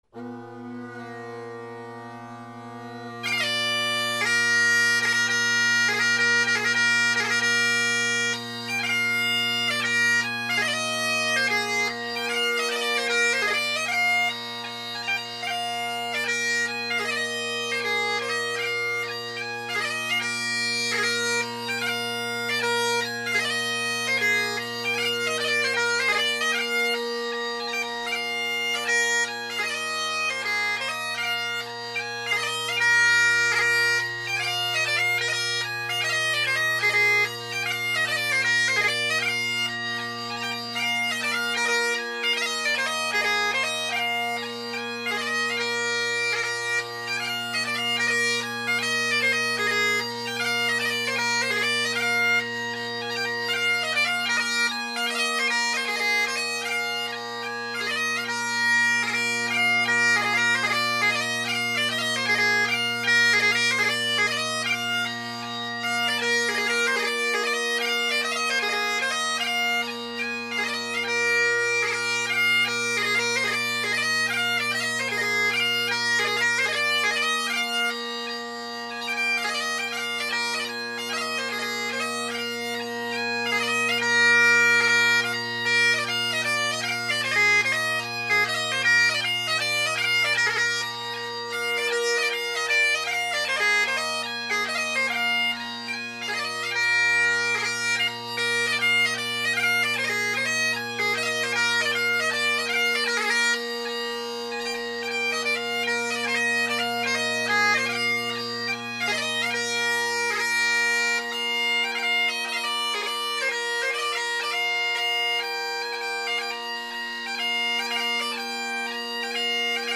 Jeannie Carruthers, Susan MacLeod, Murdo MacGillivray of Eoligarry – 2016-11-21 – Need to omit the pickup into Eoligarry and break right into it.